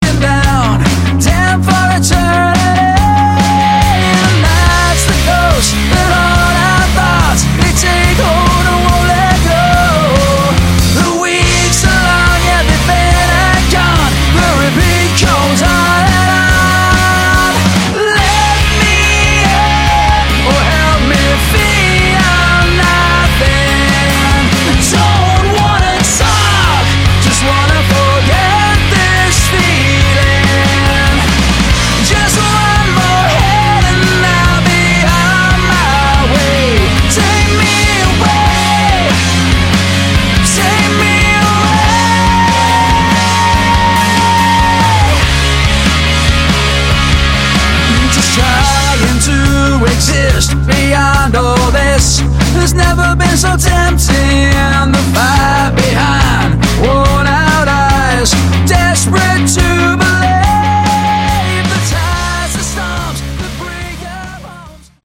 Category: Hard Rock
vocals
lead guitar
rhythmn guitar
bass
drums